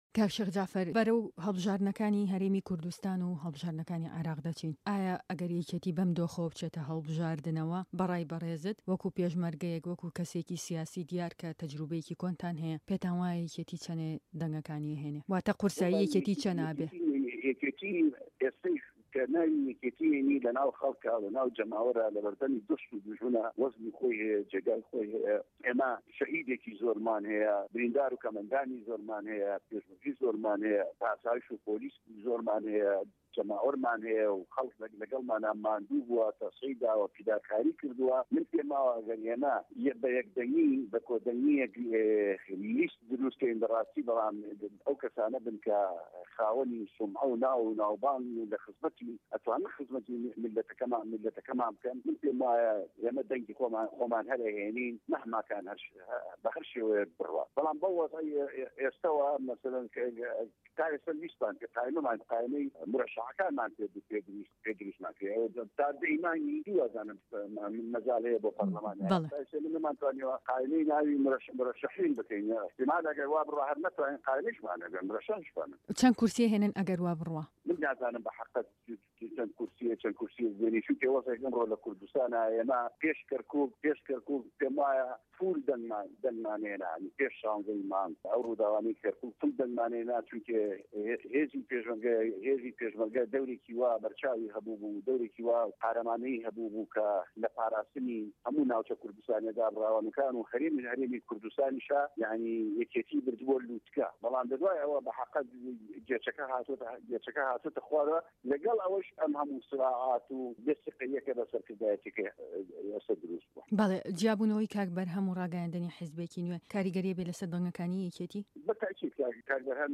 گفتوگۆكه‌ی